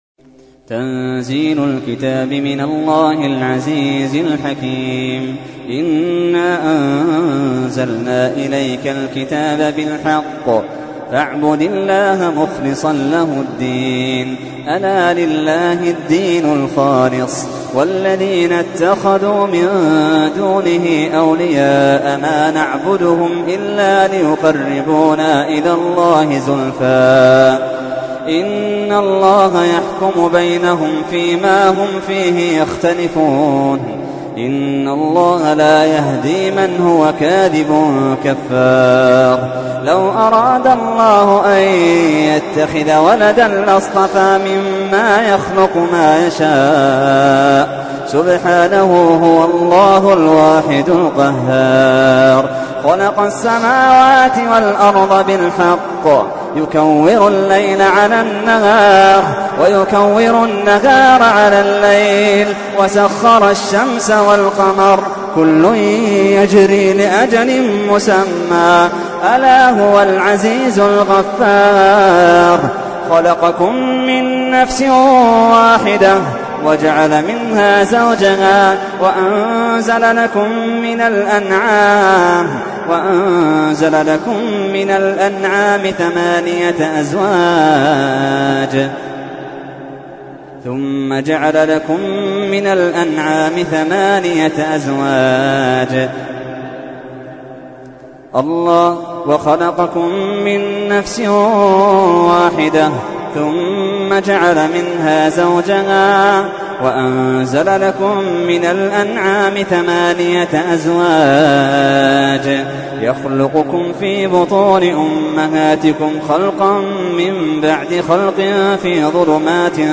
تحميل : 39. سورة الزمر / القارئ محمد اللحيدان / القرآن الكريم / موقع يا حسين